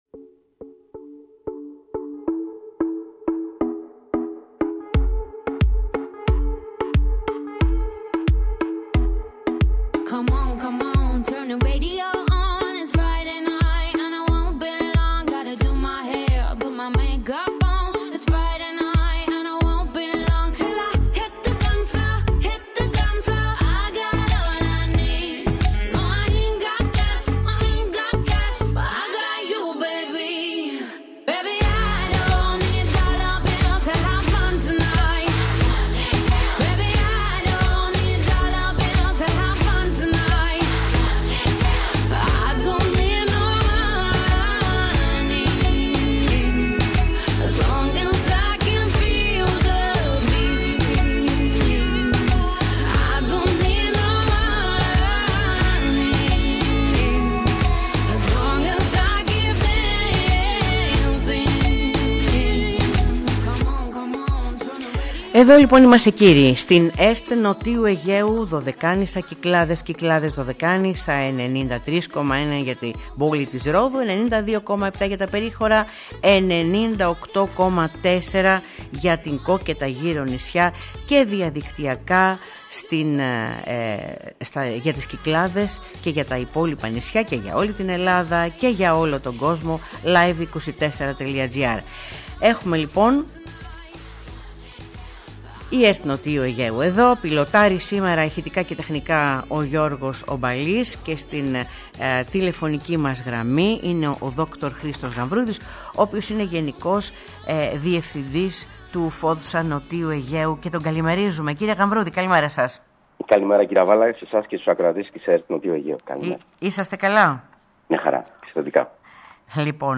Στη σημερινή εκπομπή της Τρίτης 24 Ιουνίου, στο πρώτο μέρος ακούσαμε τη συνέντευξη του Γεν.
Στο δεύτερο μέρος της εκπομπής ακούσαμε τη συνέντευξη της Δημάρχου Τήλου Μαρίας Καμμά, για τον τουρισμό στην Τήλο και την εφαρμογή του Προγράμματος Ανακύκλωσης Just Go Zero (πρώτη πιλοτική εφαρμογή σε παγκόσμιο επίπεδο).